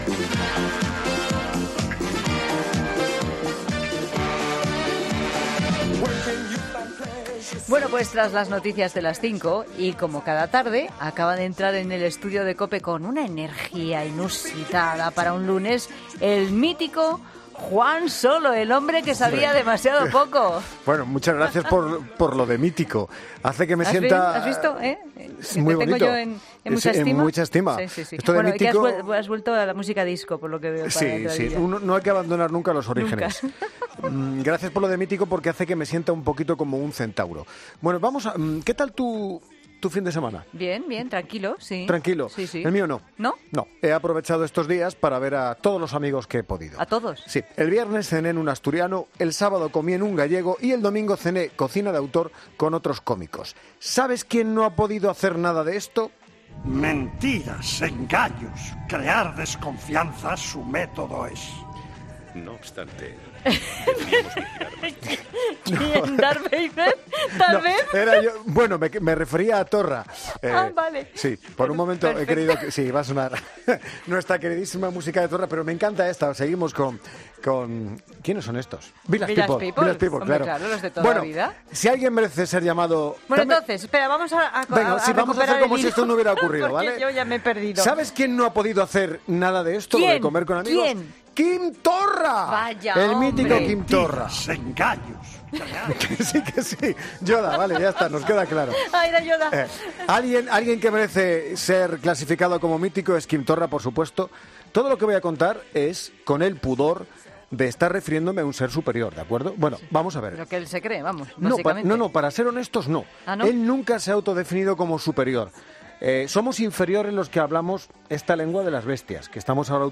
Humor: 'Adelgaza con el independentismo'